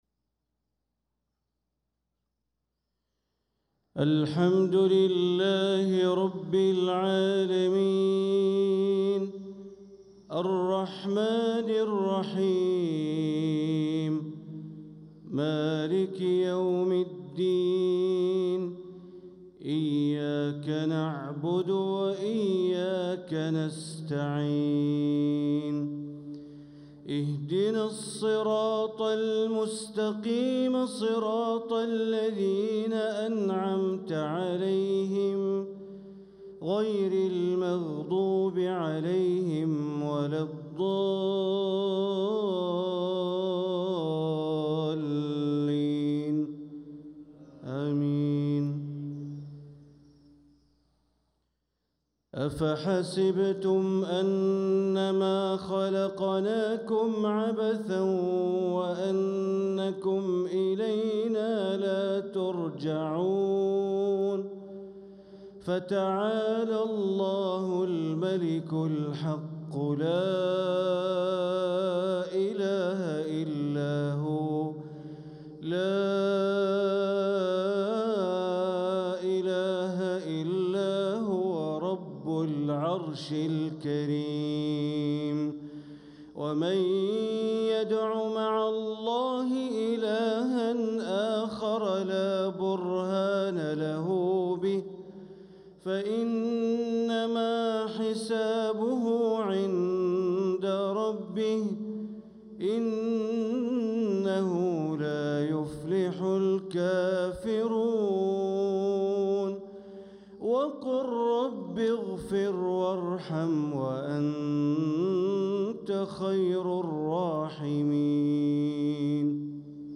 صلاة المغرب للقارئ بندر بليلة 18 ربيع الأول 1446 هـ